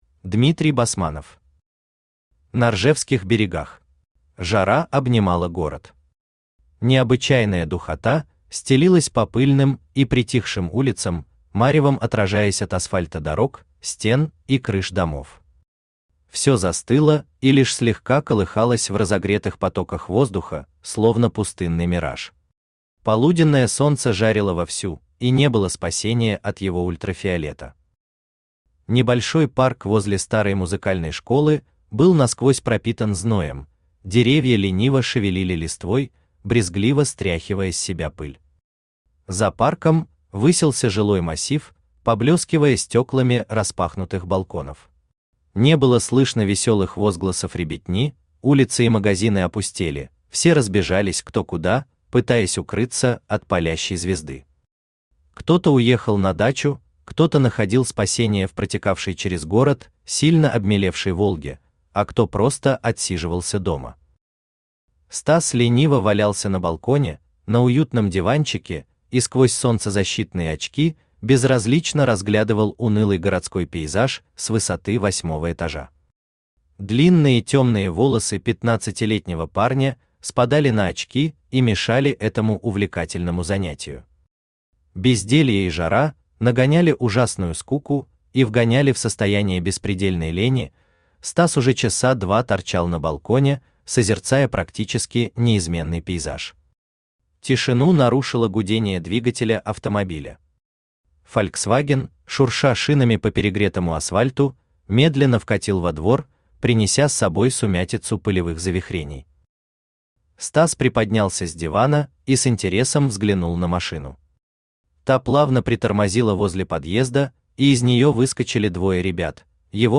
Аудиокнига На ржевских берегах | Библиотека аудиокниг
Aудиокнига На ржевских берегах Автор Дмитрий Викторович Басманов Читает аудиокнигу Авточтец ЛитРес.